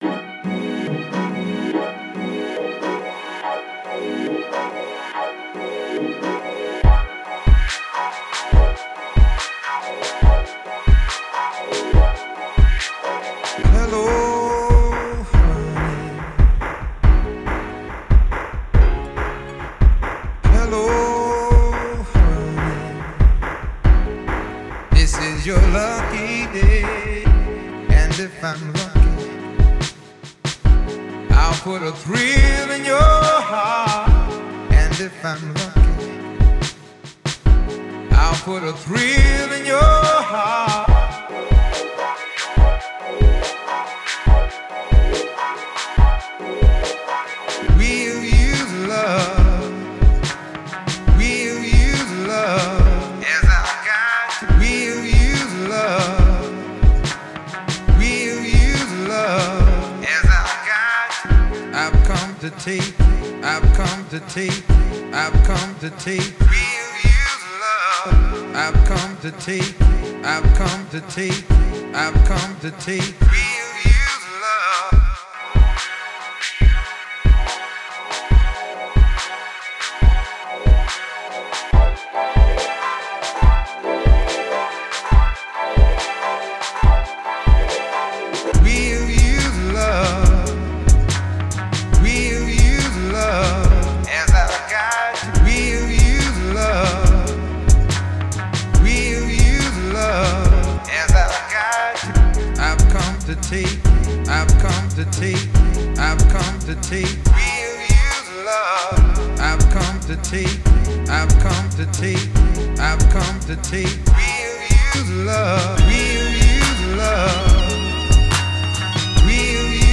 My track was done on SP 404 mk2. Added a synthbass, some leads, some drumsamples.
The mix is a realtime resample of the patterns I made.
Lovely smooth track.
Thanks, synthleads were played live; SP skipback works really nice for catching the good riff.